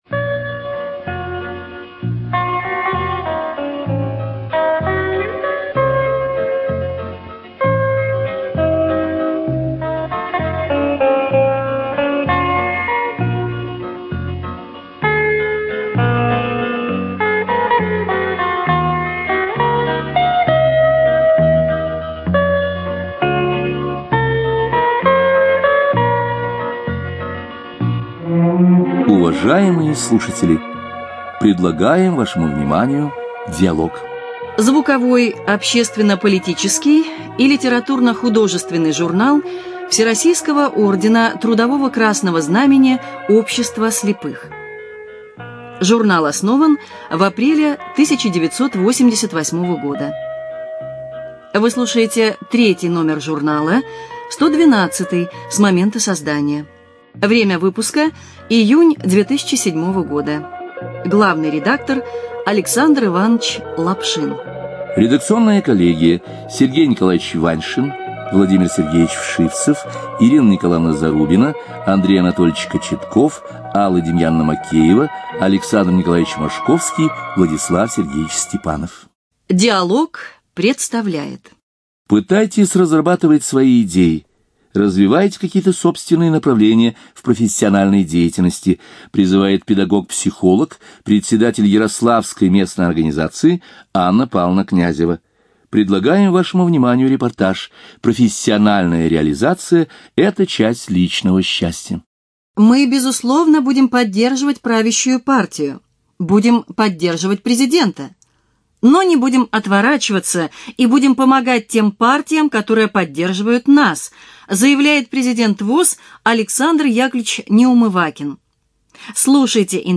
ЖанрРеабилитация, Публицистика, Документальные фонограммы
Студия звукозаписиЛогосвос